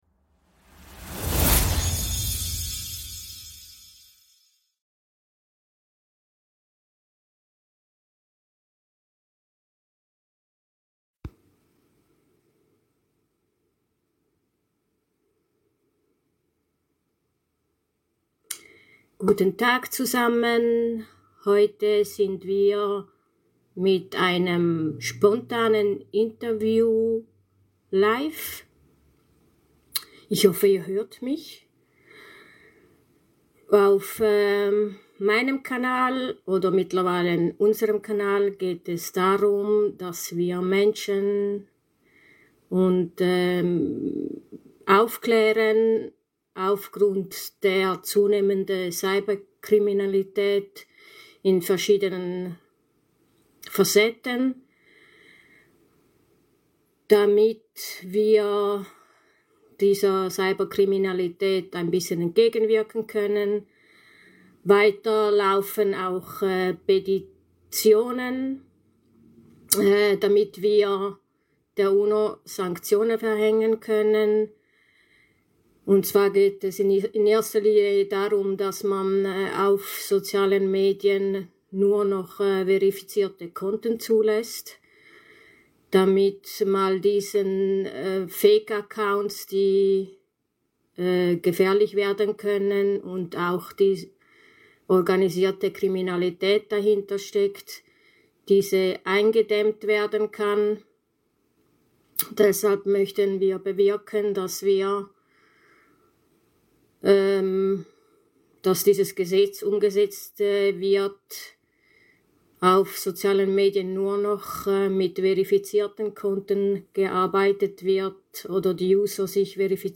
Gefälschte Accounts auf Sozialen Medien - Fake Accounts - Gefahr - im Interview - Organisiertes Verbrechen ~ True Crime - Kriminalfälle - Cybercrime - organisierte Kriminalität - auch Fiktion Podcast